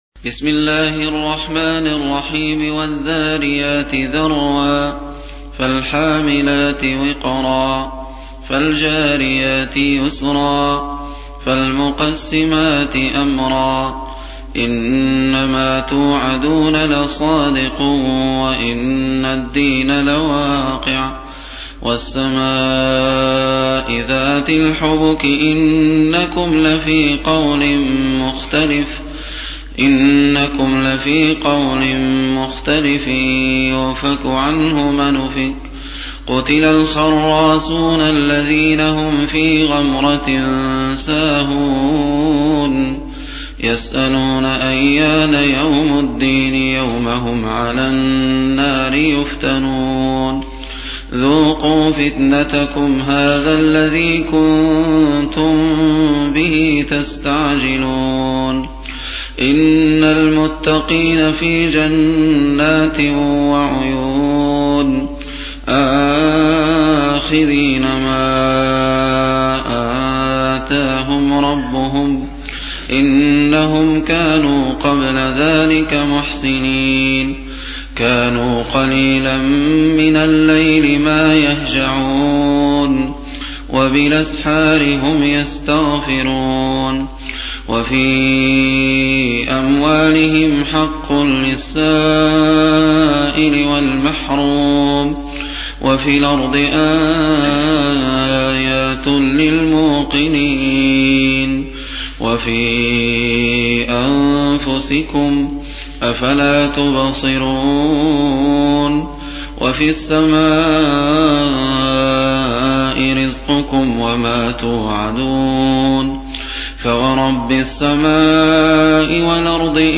51. Surah Az-Z�riy�t سورة الذاريات Audio Quran Tarteel Recitation
Surah Repeating تكرار السورة Download Surah حمّل السورة Reciting Murattalah Audio for 51.